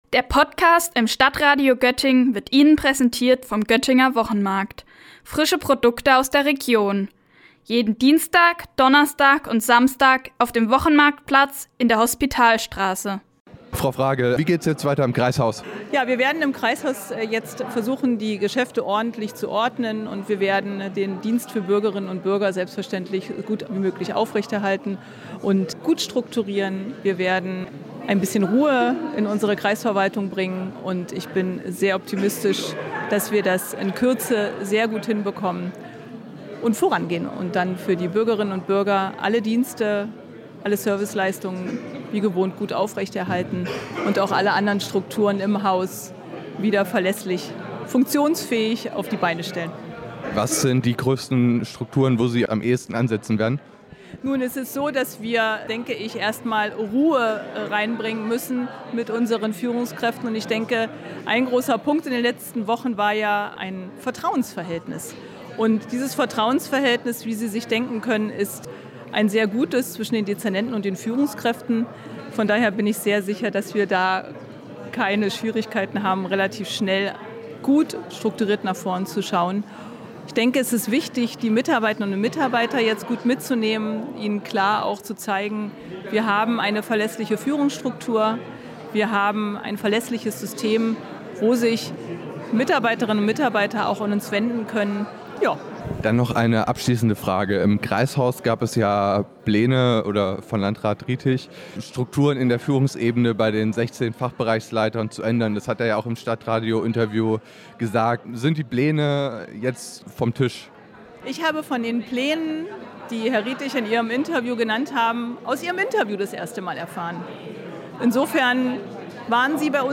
Beiträge > Doreen Fragel im Interview: Sie vertritt nun Landrat Riethig - StadtRadio Göttingen